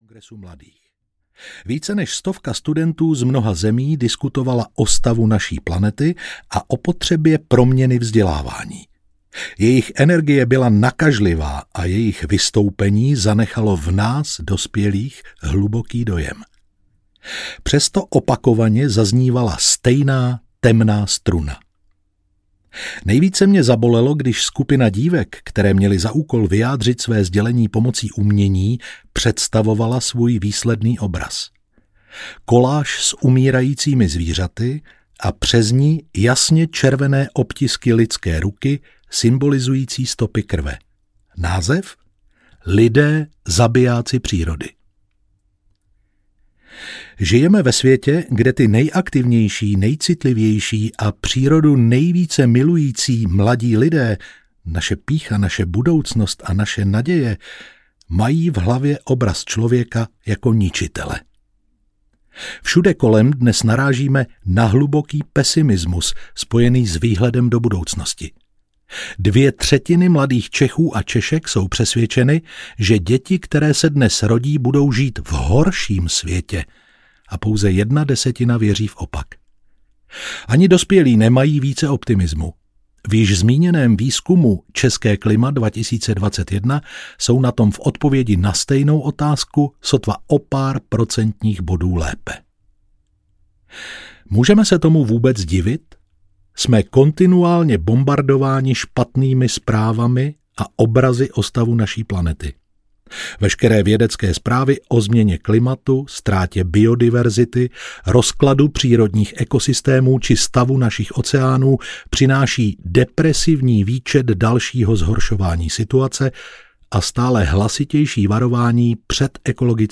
Audio kniha
Ukázka z knihy
klima-je-prilezitost-audiokniha